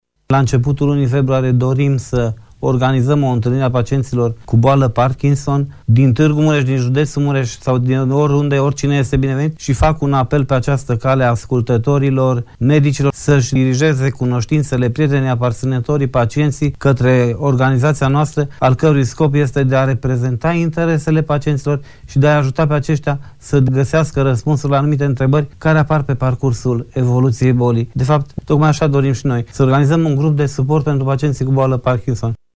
Invitat în studioul Video Fm